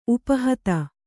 ♪ upa hata